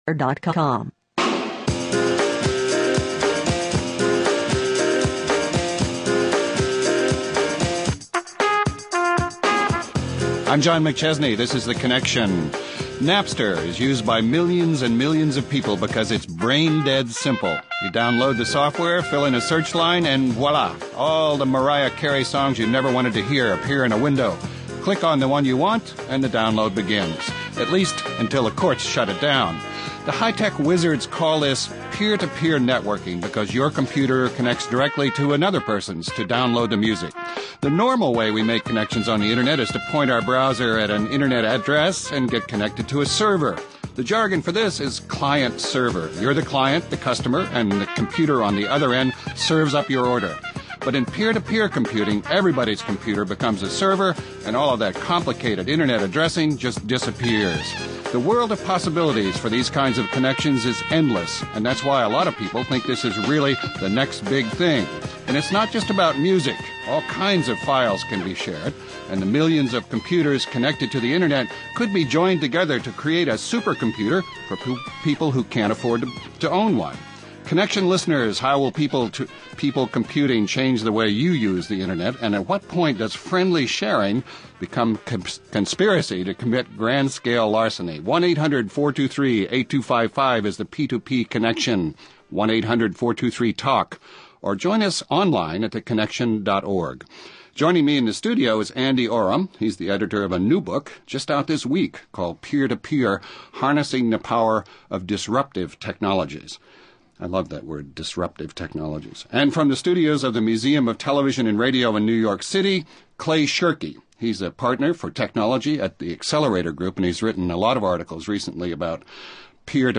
We’re taking it to the tundra and exploring the truth about Anwar. (Hosted by Christopher Lydon)